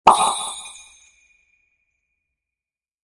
healpop-46004.mp3